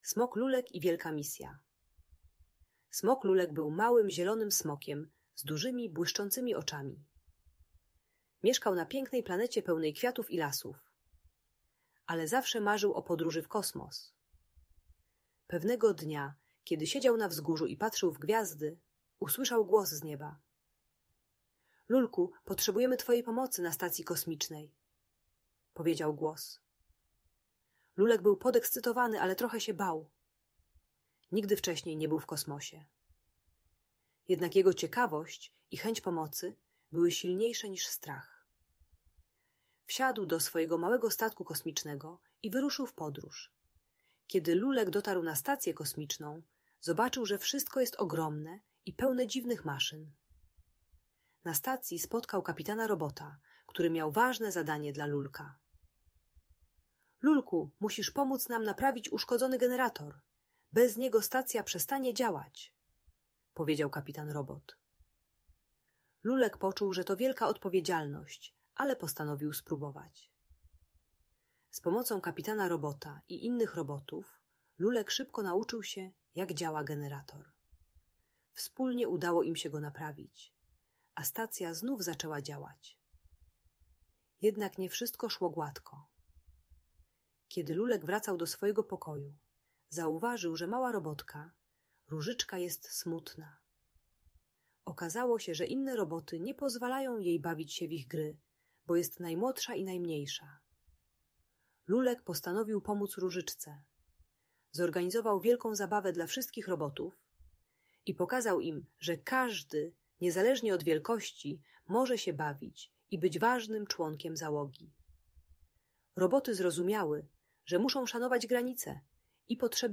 Przygody Smoka Lulka - Audiobajka